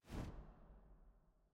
sfx_ui_map_settlement_deselect.ogg